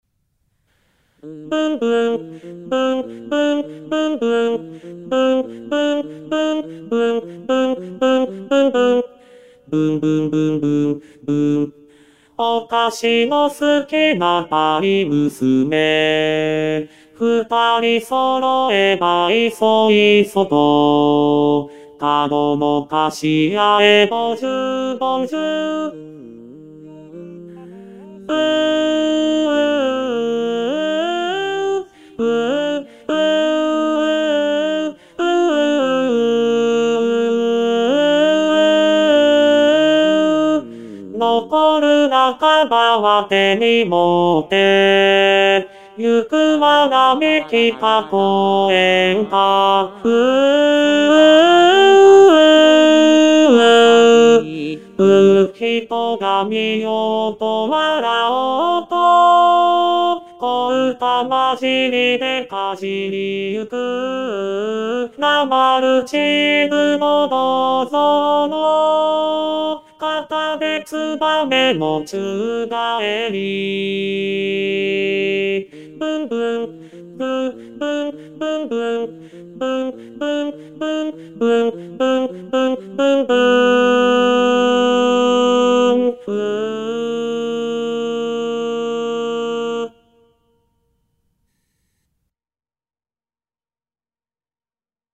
★第１２回定期演奏会　演奏曲　パート別音取り用 　機械音声(ピアノ伴奏希望はｽｺｱｰﾌﾟﾚｱｰsdxで練習して下さい)